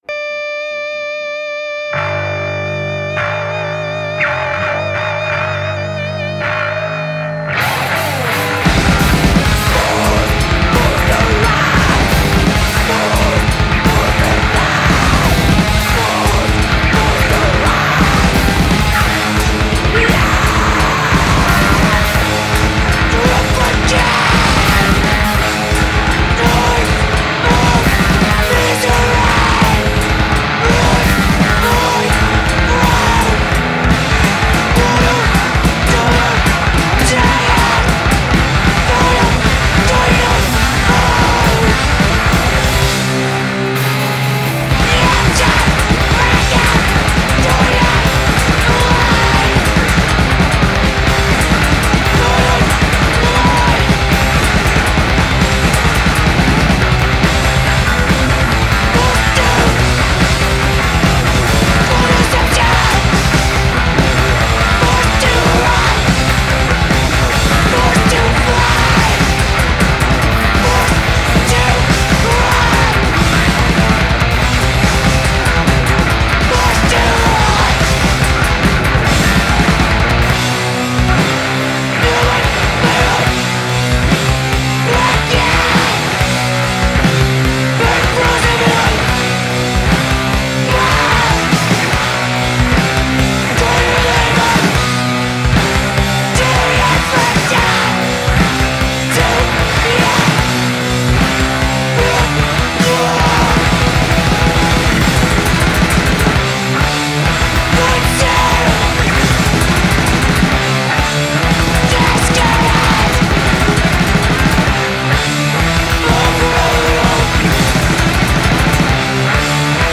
painful shouts